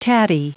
Prononciation du mot tatty en anglais (fichier audio)
Prononciation du mot : tatty